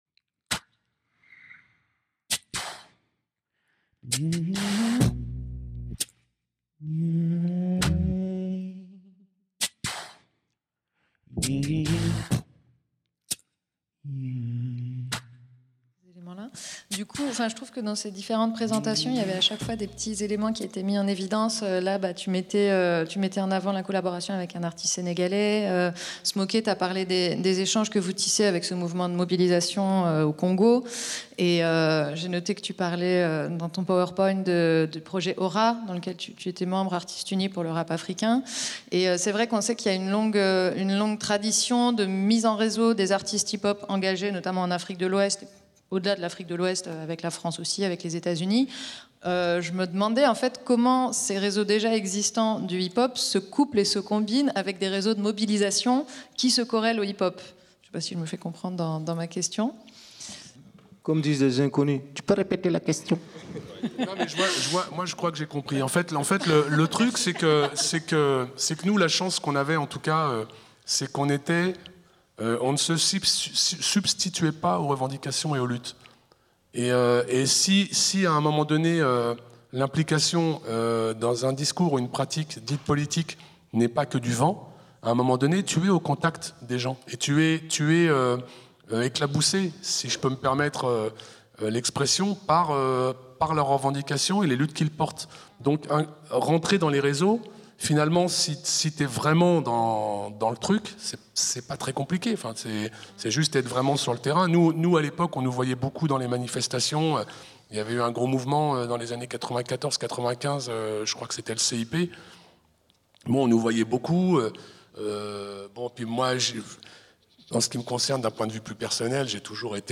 Table Ronde Des artistes hip-hop au cœur des mobilisations politiques : exemples internationaux (2) | Canal U